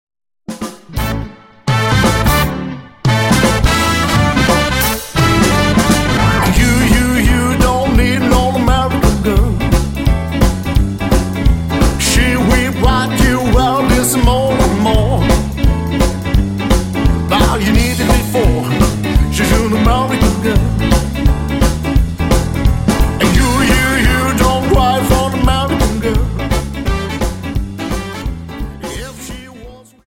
Dance: Jive 43